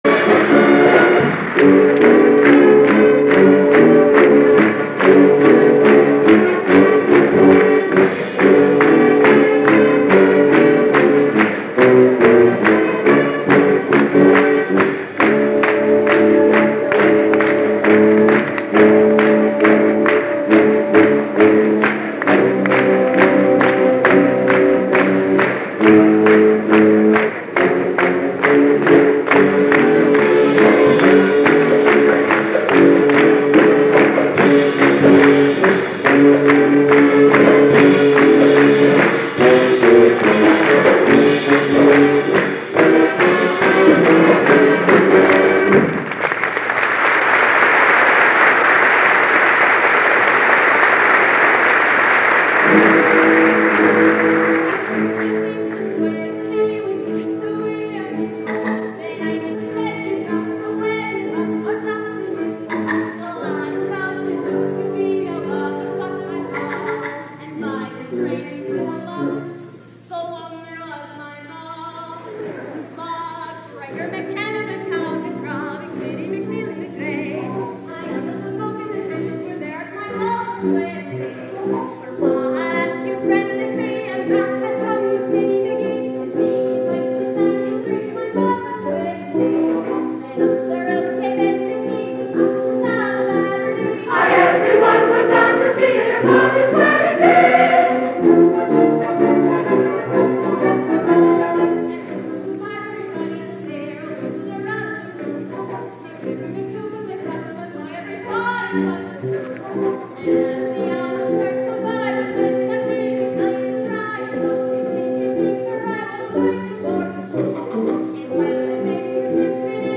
From a drawer somewhere, stored away for all those years on an old and nearly forgotten reel-to-reel tape in miraculously good condition, the ghost of Brigadoon has arisen to speak to us.
All musical selections include the surrounding dialogue.
chorus